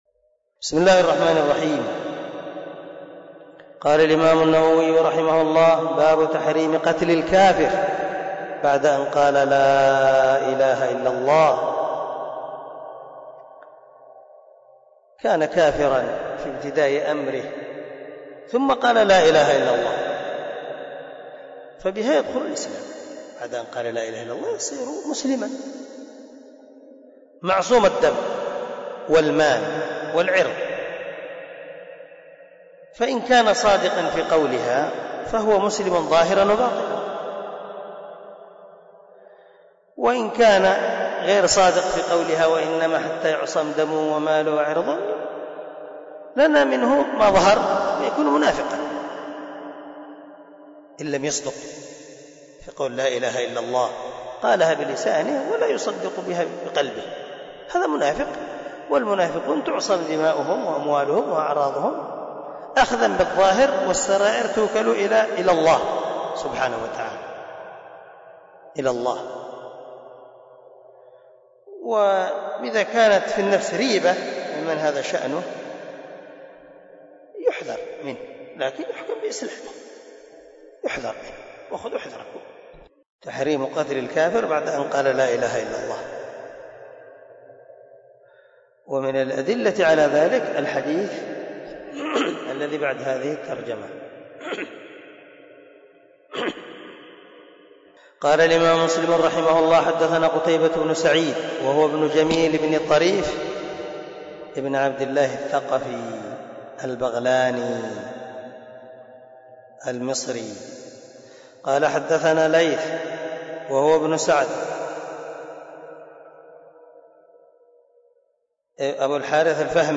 066الدرس 65 من شرح كتاب الإيمان حديث رقم ( 95 ) من صحيح مسلم